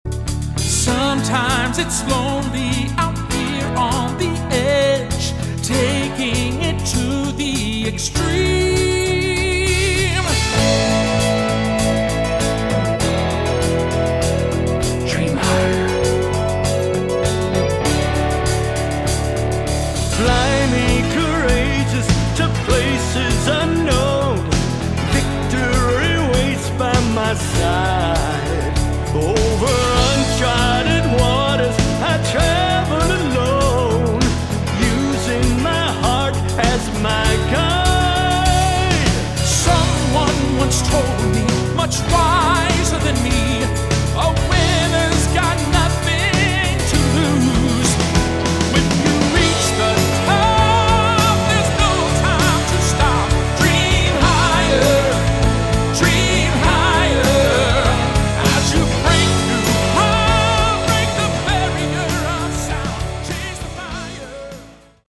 Category: AOR
drums, percussion